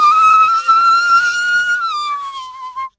Kettle Whistle
A tea kettle reaching boiling point with rising whistle tone and steam release
kettle-whistle.mp3